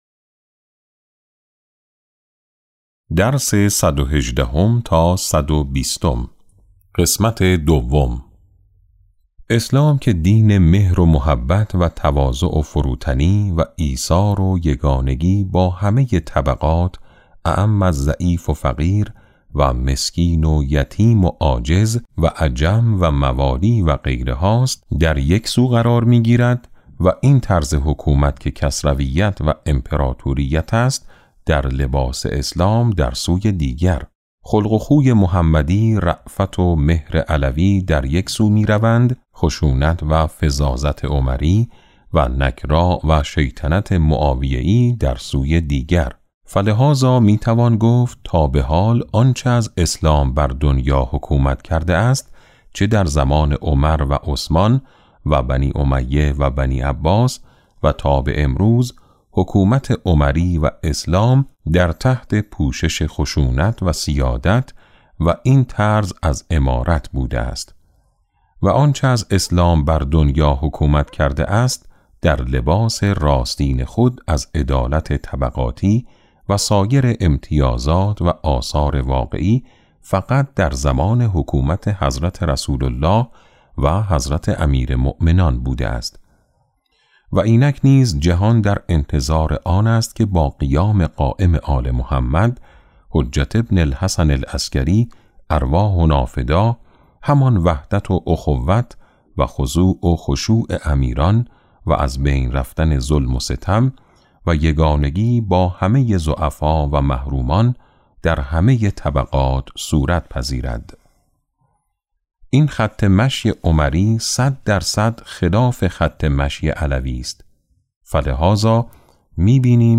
کتاب صوتی امام شناسی ج۸ - جلسه12